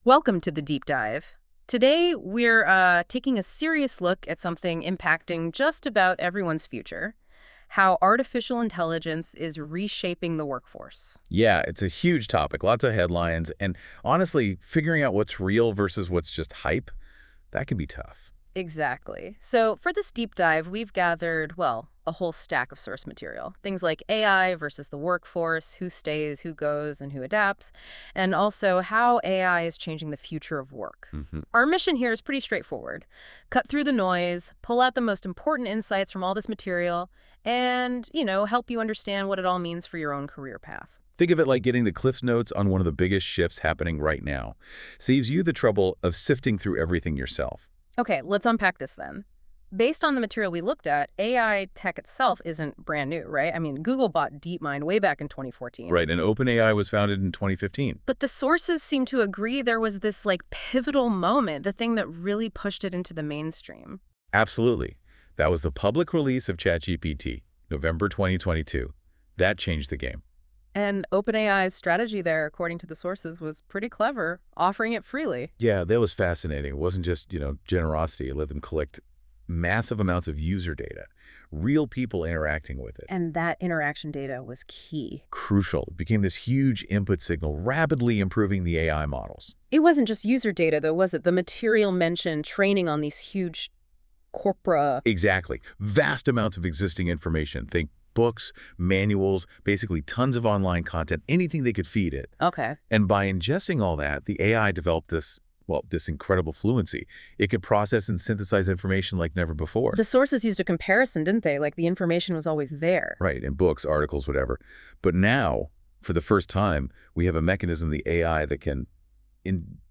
Listen 👇 to the article in interview style conversation produced by Google AI.